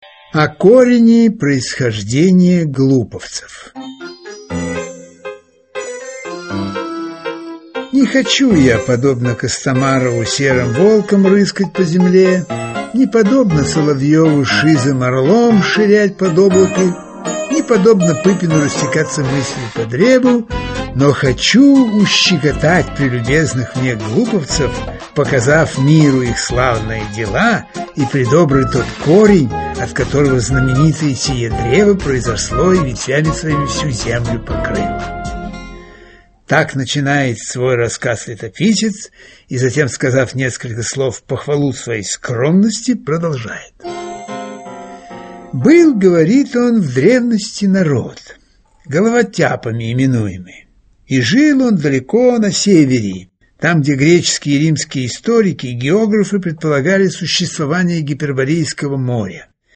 Аудиокнига История одного города | Библиотека аудиокниг
Aудиокнига История одного города Автор Михаил Салтыков-Щедрин Читает аудиокнигу Михаил Ульянов.